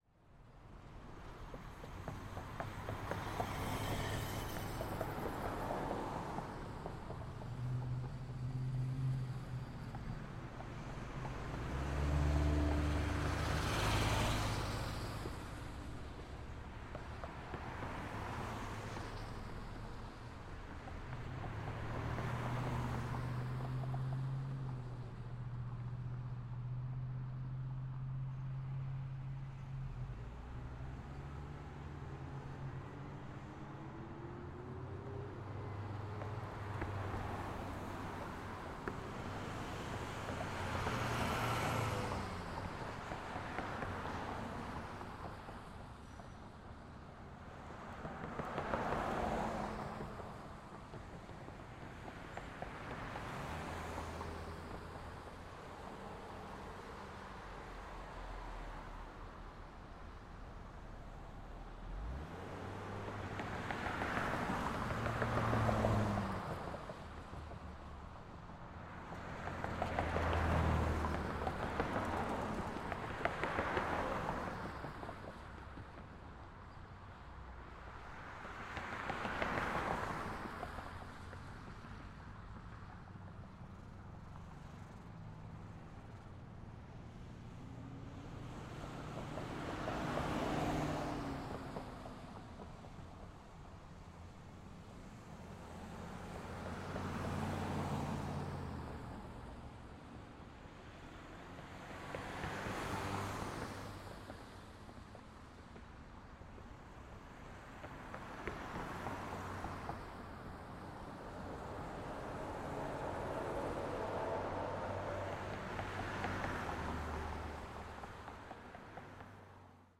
Street Traffic.mp3